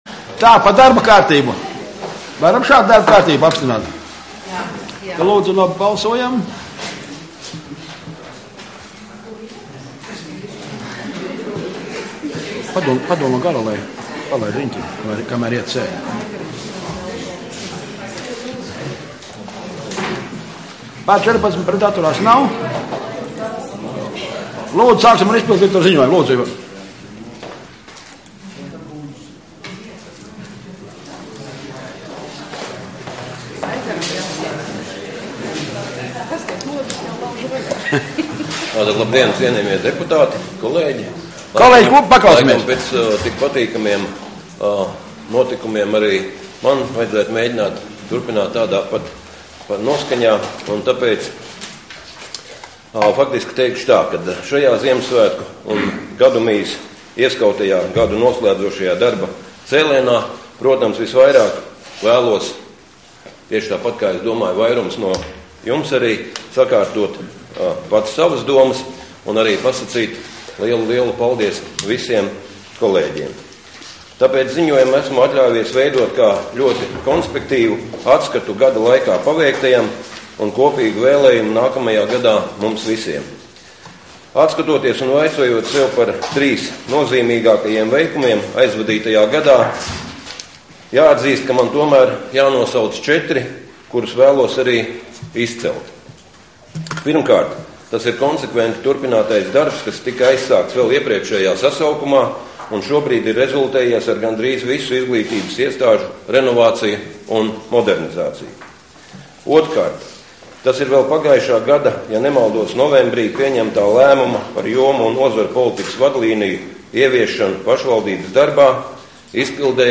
Domes sēde Nr. 21